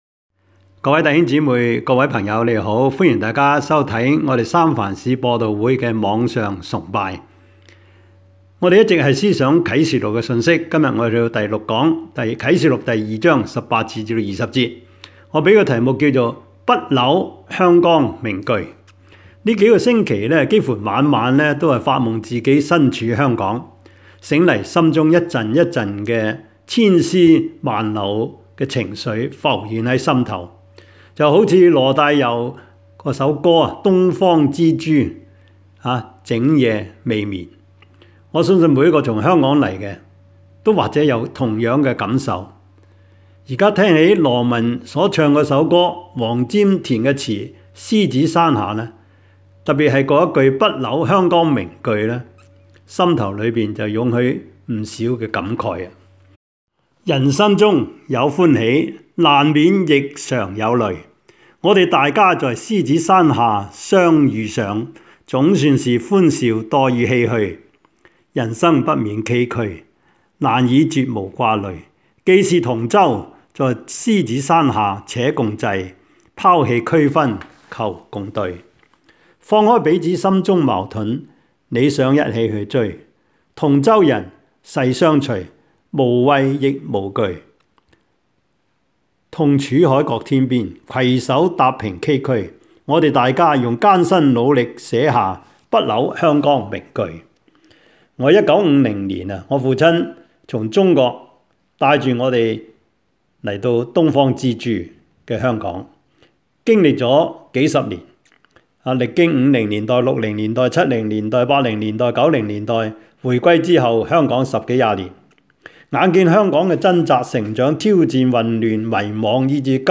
Service Type: 主日崇拜
Topics: 主日證道 « 生命重建的材料 門徒本色1 : 來跟從我 »